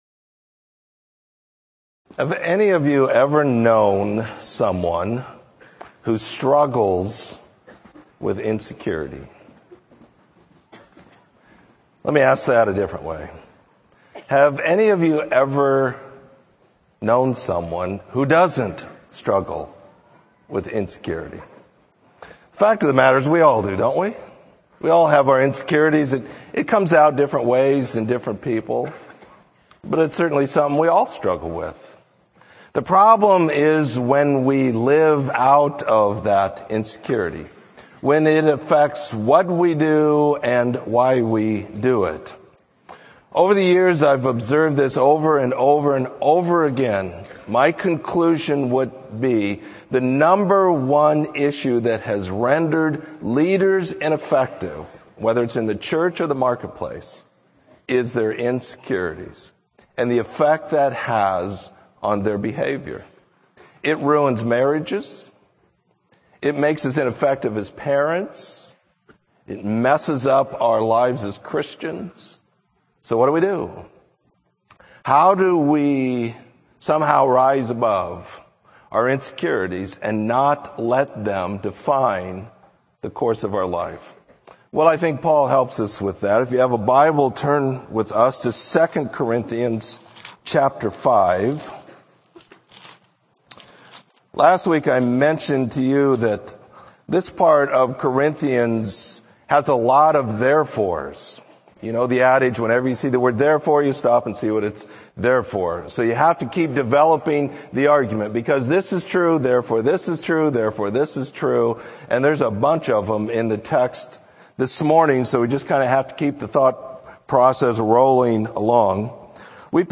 Sermon: Ambassadors for Christ